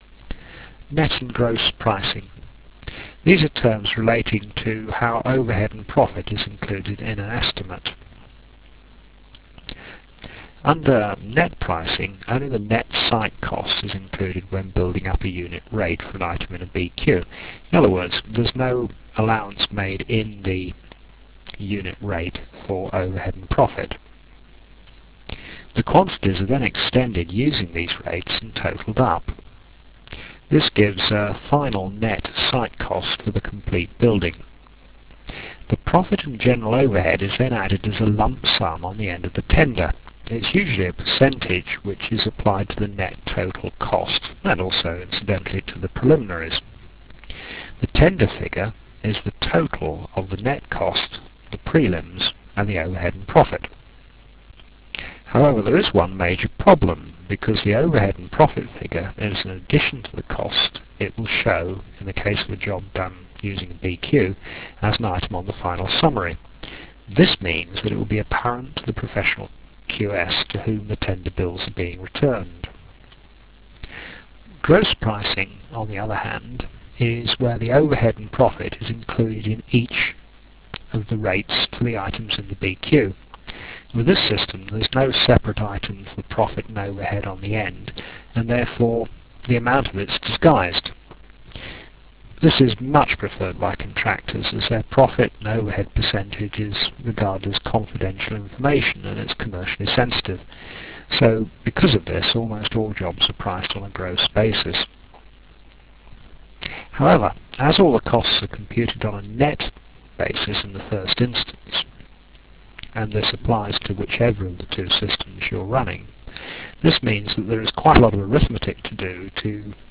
The sound quality is adequate but low fi.
Enjoy your lectures.........